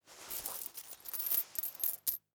household
Coins in Pants Pocket Moving